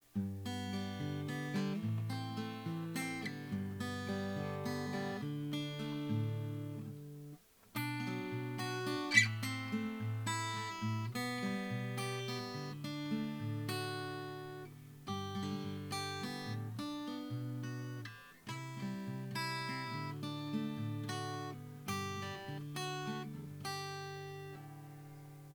I mostly play finger style so I recorded the same piece on both guitars using the same microphone and software. The sound difference was negligible.
The Andrew White guitar has a laminated Indian Rosewood back and sides, Solid Sitka Spruce top, scalloped bracing.